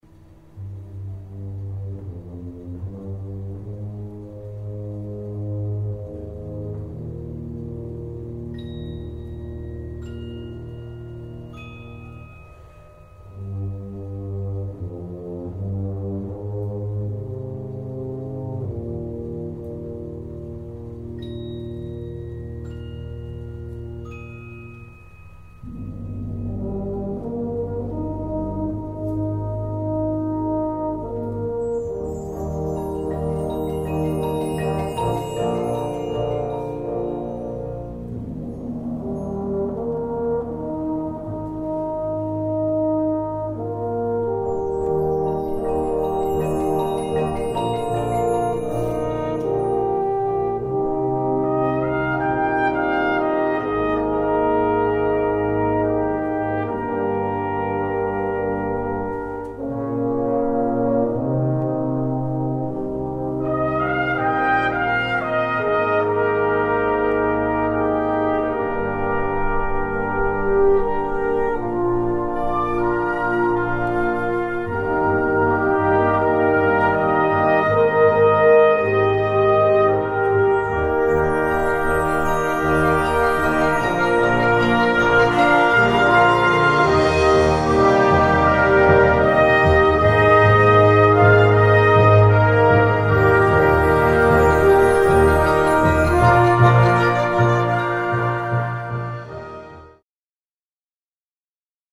Gattung: Konzertwerk
A4 Besetzung: Blasorchester Tonprobe